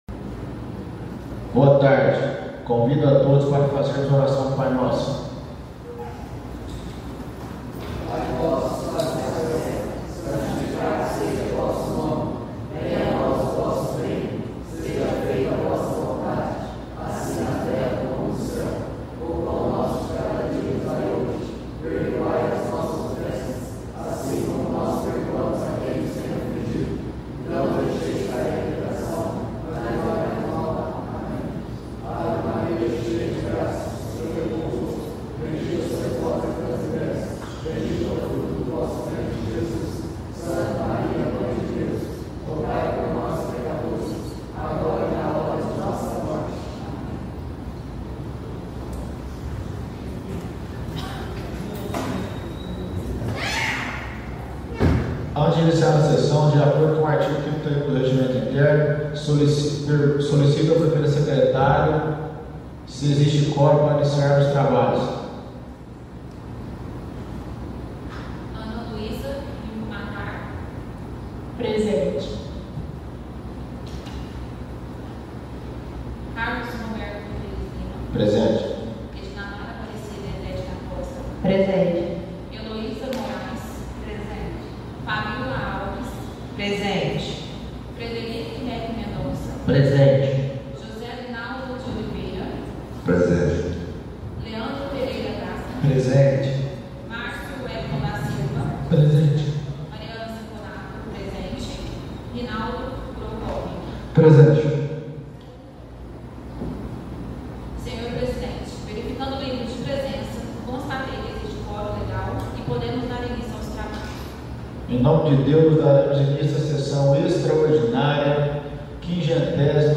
Áudio da Sessão Extraordinária - 27.01.2025